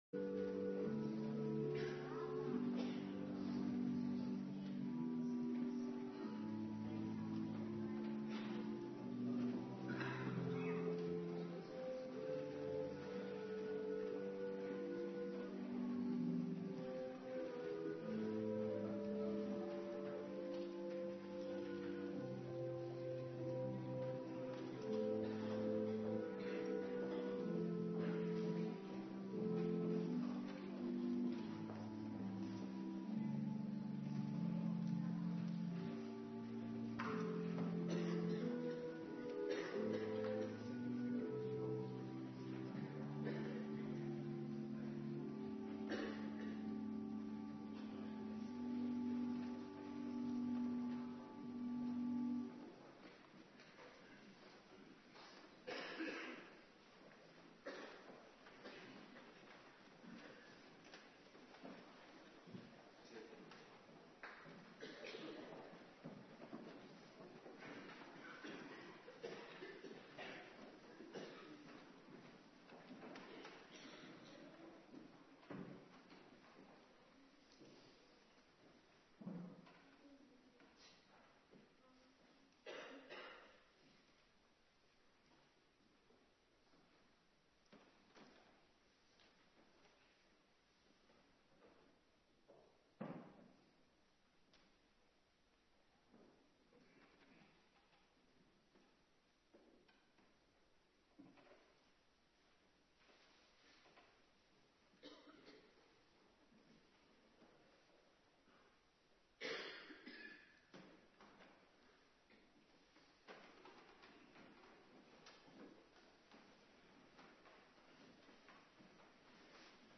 Morgendienst Heilig Avondmaal
09:30 t/m 11:00 Locatie: Hervormde Gemeente Waarder Agenda